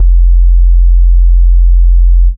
Bass (9).wav